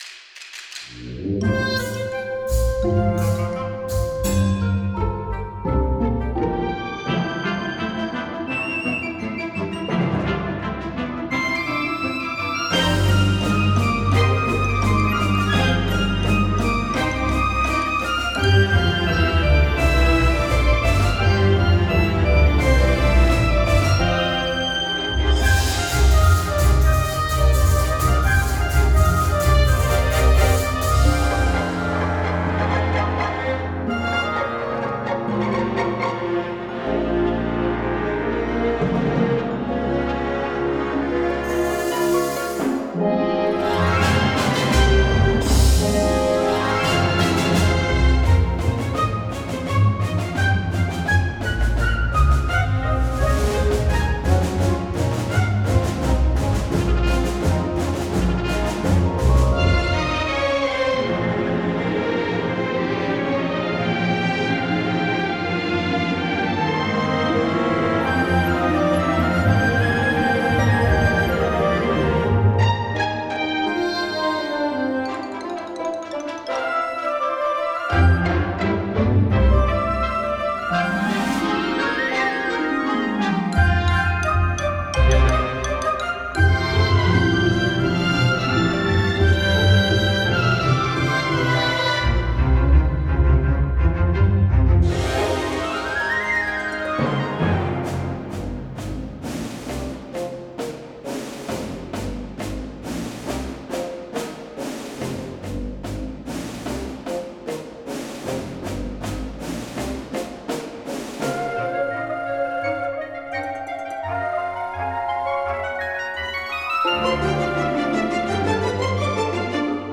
2018 play_arrow An orchestral and bouncy remix.